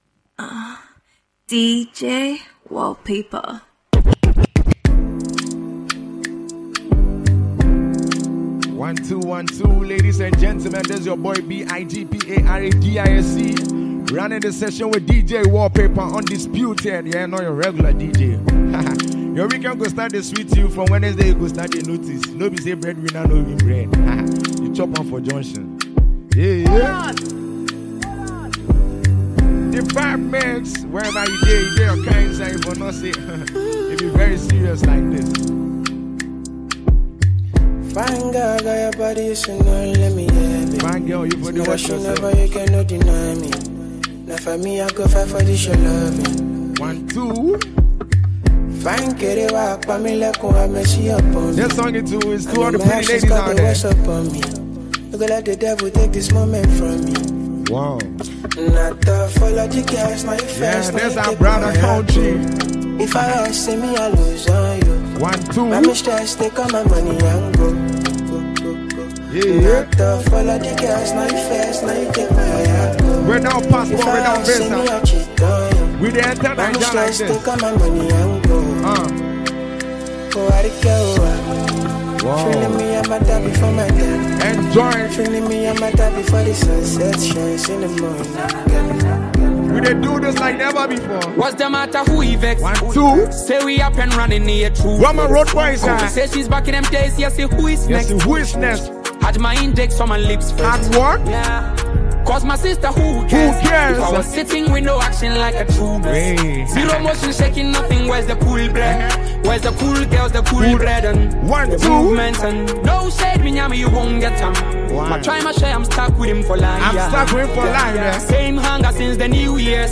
a super talented Ghanaian disc jockey.
drops a new mixtape
the hypeman.